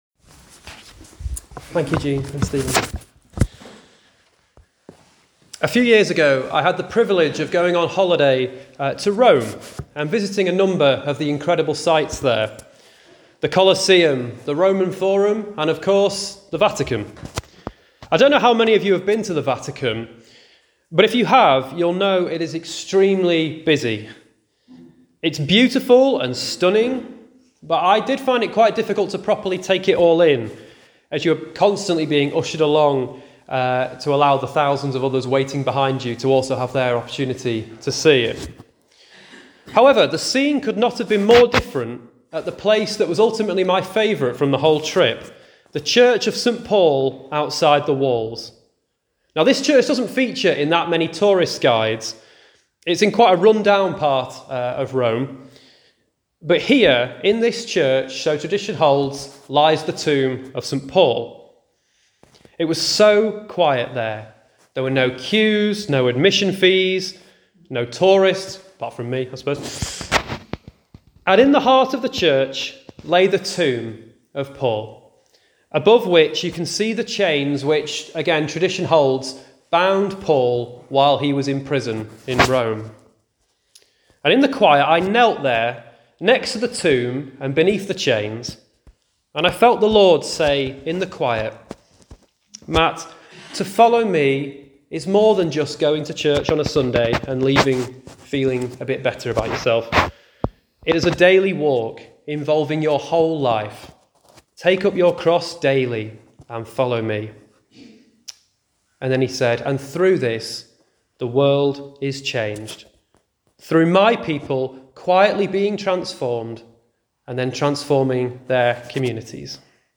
Sunday Sermons